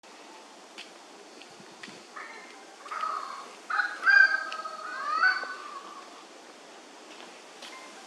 Nombre científico: Megapodius reinwardt
Nombre en inglés: Orange-footed Scrubfowl
Localidad o área protegida: Lamington National Park
Condición: Silvestre
Certeza: Vocalización Grabada
orange-footed-megapode.mp3